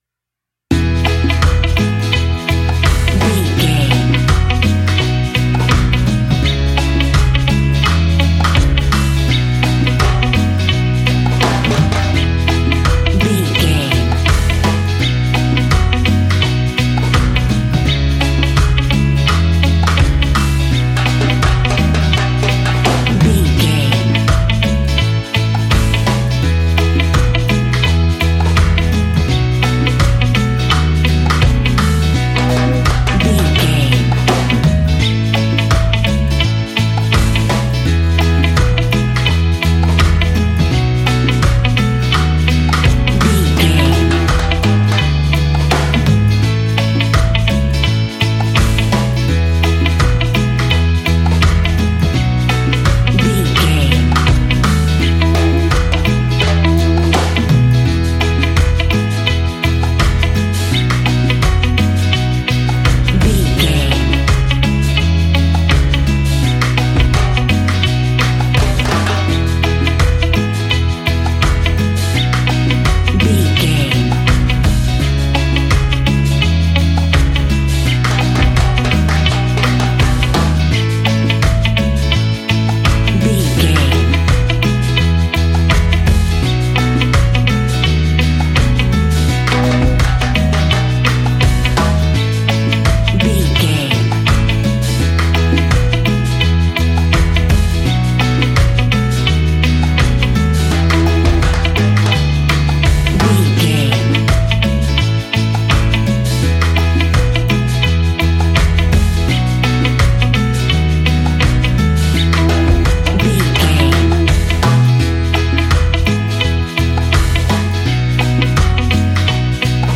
Ionian/Major
Slow
steelpan
calpso groove
drums
brass
guitar